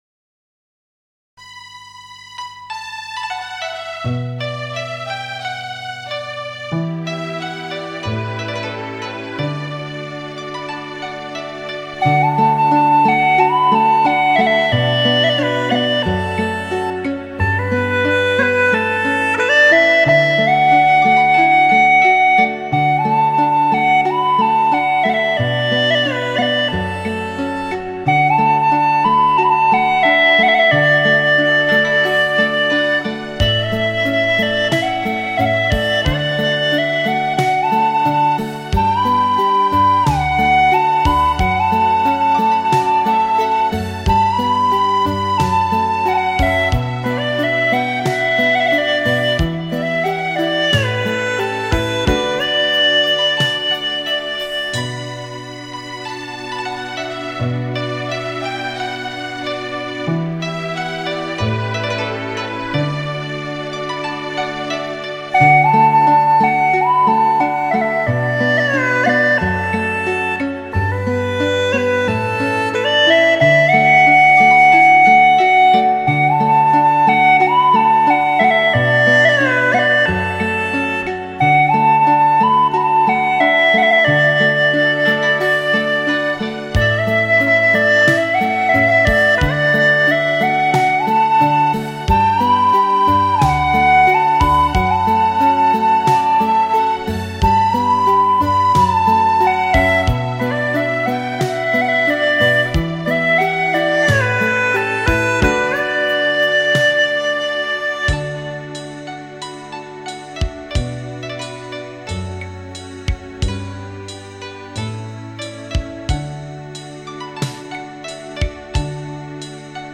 葫芦丝常用于吹奏山歌等民间曲调，适于演奏旋律流畅的乐曲和舞曲，乐声柔美和谐，能较好抒发演奏者的思想感情。
柔情似水的醉人旋律
A) 葫芦丝